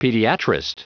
Prononciation du mot pediatrist en anglais (fichier audio)
Prononciation du mot : pediatrist